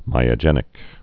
(mīə-jĕnĭk) also my·o·ge·net·ic (mīō-jə-nĕtĭk)